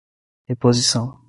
Pronunciado como (IPA)
/ʁe.po.ziˈsɐ̃w̃/